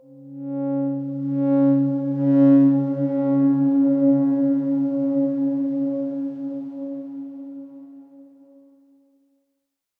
X_Darkswarm-C#3-pp.wav